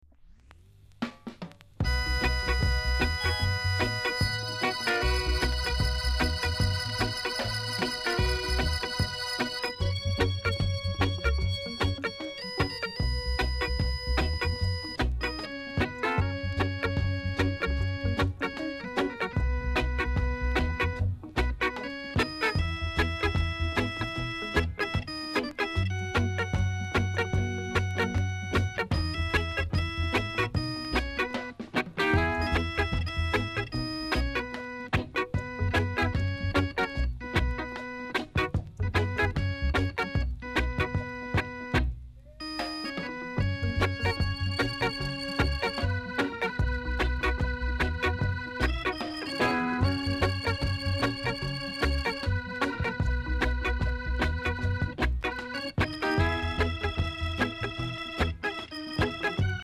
※小さなチリノイズが少しあります。
コメント RARE INST COVER!!